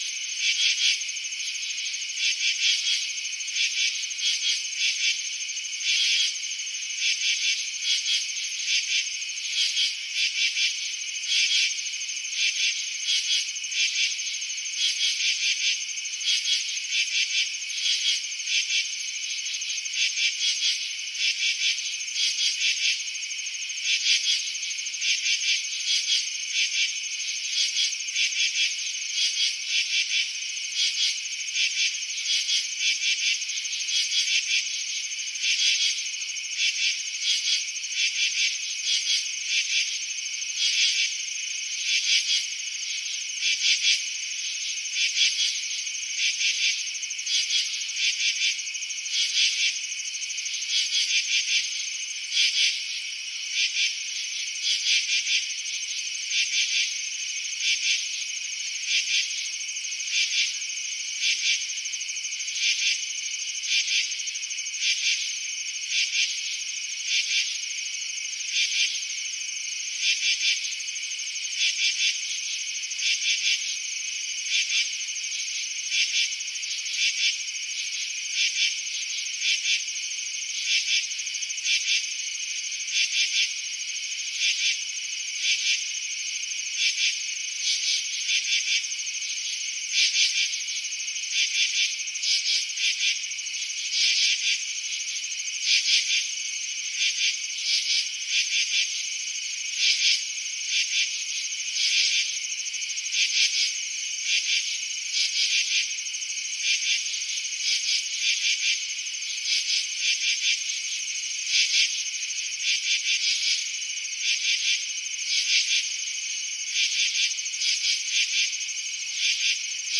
蟋蟀
描述：这是在晚上一些昆虫唱歌的声音。昆虫的合唱是由单个昆虫的录音混合和编辑在一个声音文件中。
标签： 合唱 昆虫 森林 蟋蟀声 蟋蟀
声道立体声